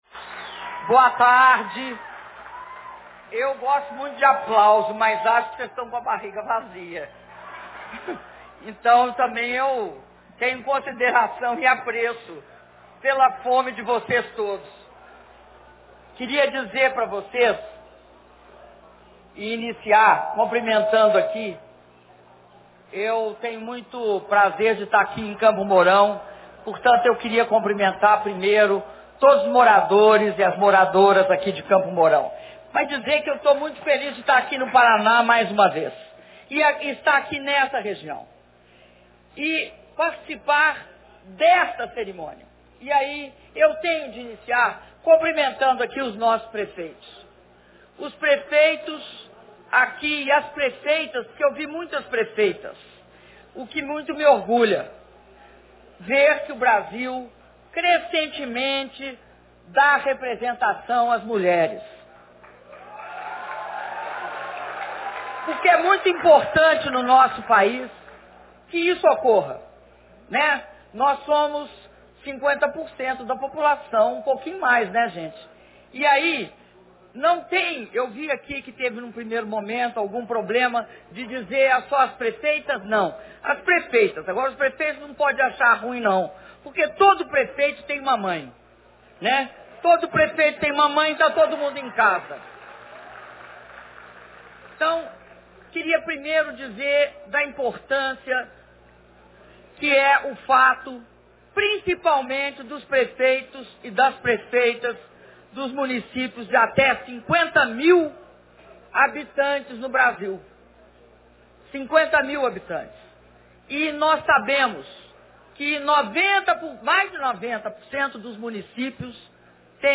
Discurso da Presidenta da República na cerimônia de entrega de máquinas e de assinatura das ordens de serviço da BR-158 e da BR-487 - Campo Mourão/PR